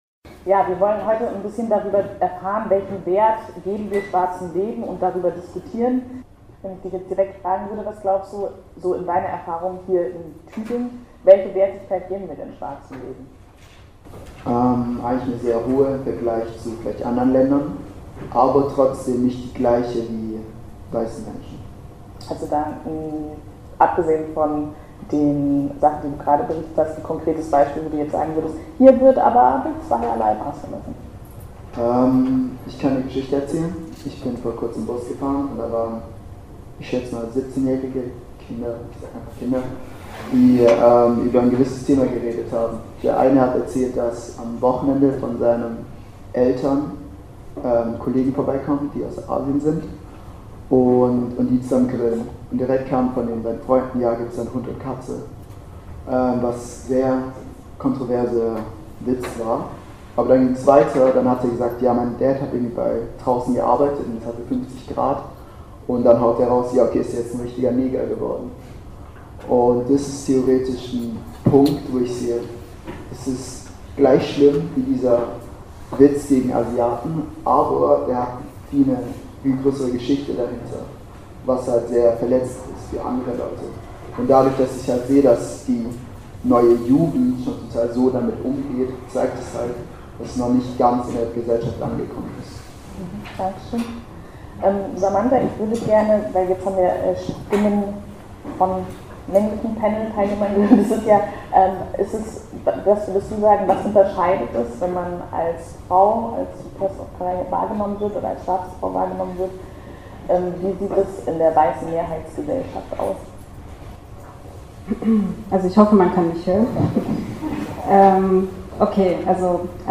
Diese Räume vor allem auch für Schwarze zu schaffen, damit sie sich miteinander austauschen und sich organisieren können, ist eine der Schlussfolgerungen der Diskussion. Alles weitere findet ihr in unserer geschnittenen Version der Podiumsdiskussion, die im Brechtbau im Rahmen von TAKT stattfand.